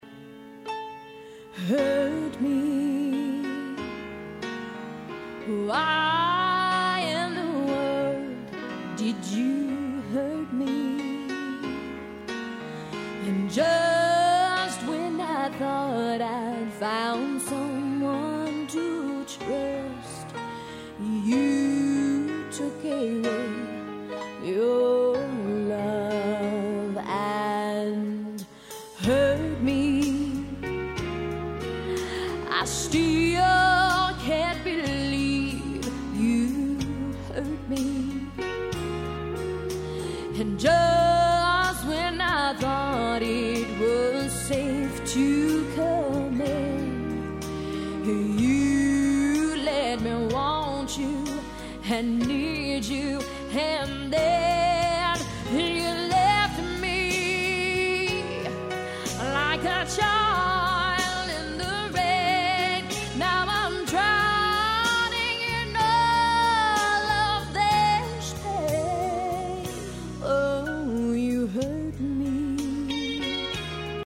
C  O  U  N  T  R  Y     C  O  V  E  R  S